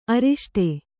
♪ ariṣṭe